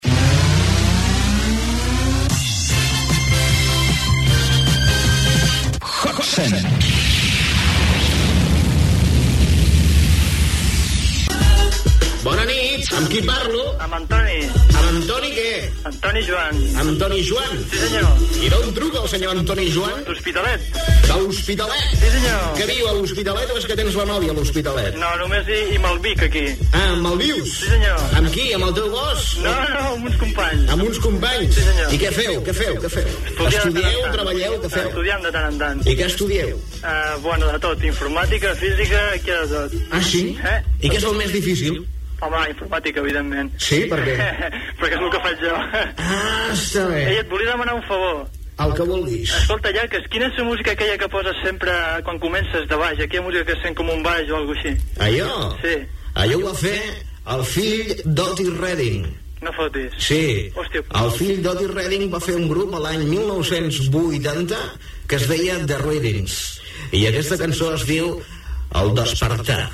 Indicatiu del programa, trucada d'un oïdor
Musical